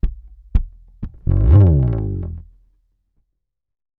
PHPH_bass_slide_02_120.wav